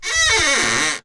01DoorOpen.ogg